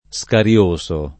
scarioso [ S kar L1S o ]